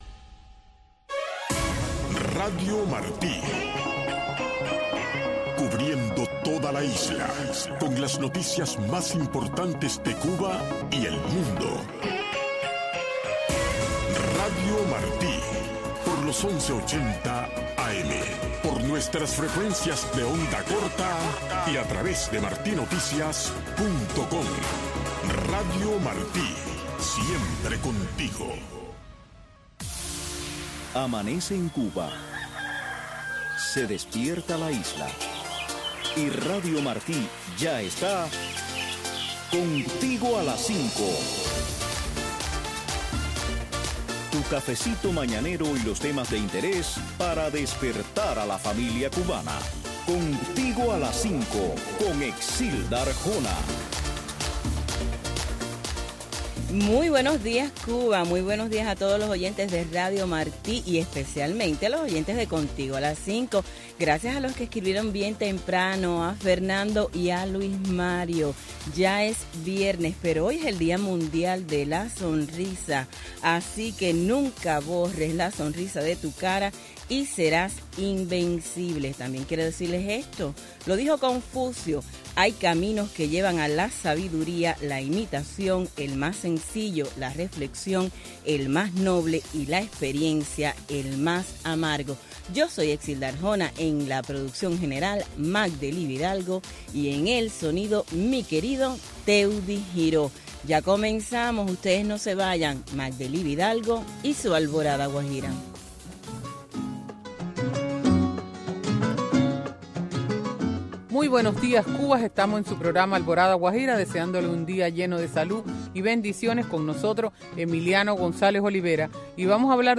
Revista informativa, cultural, noticiosa